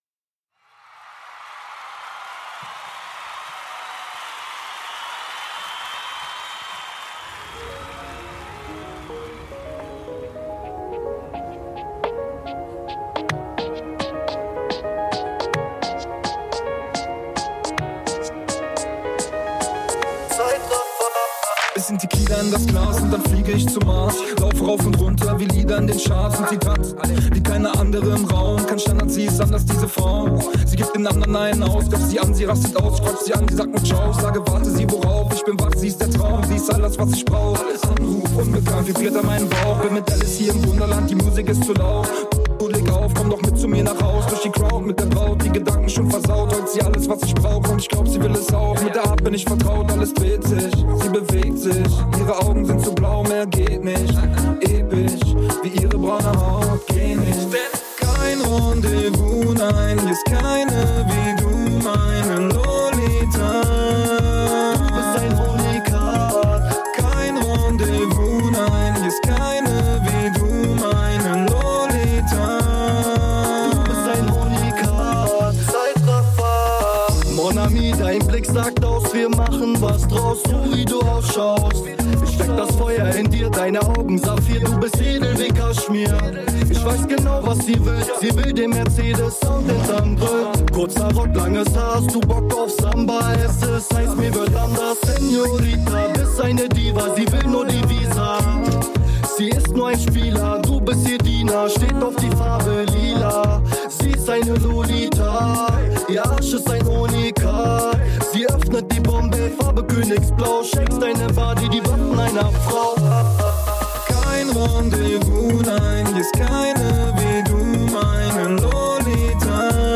NRWtv__Reportage_Zeitraffer(1).mp3